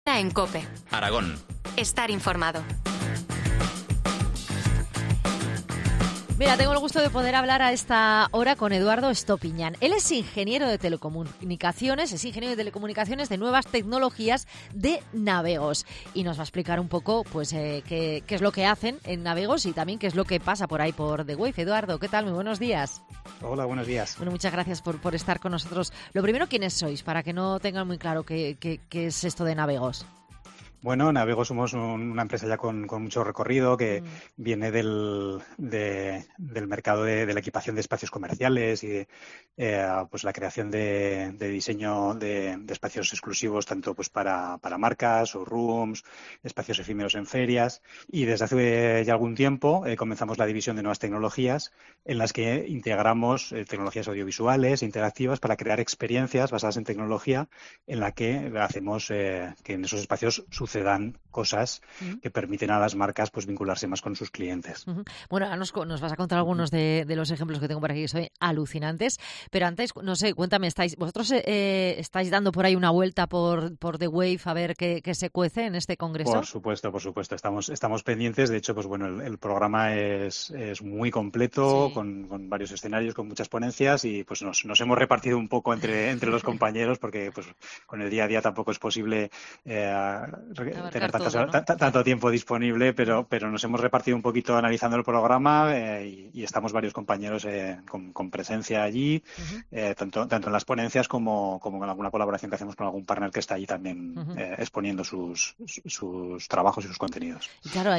Entrevista sobre The Wave